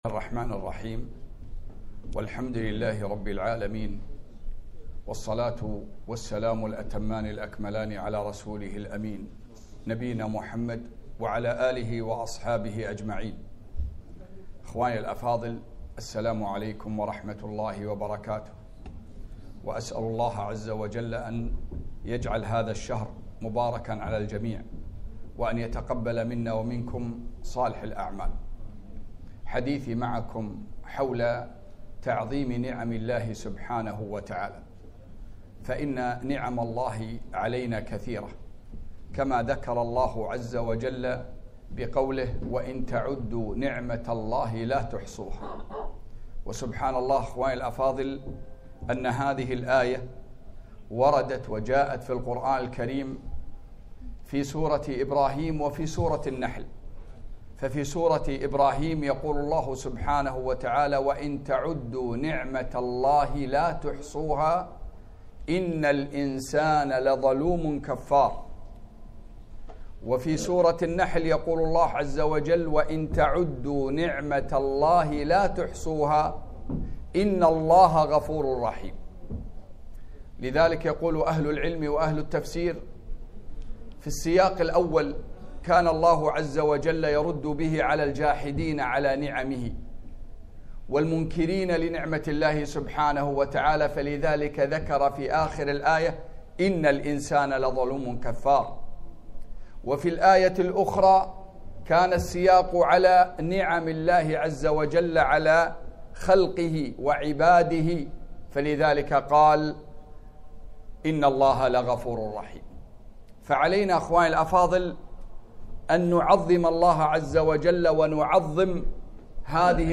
كلمة - تعظيم النعم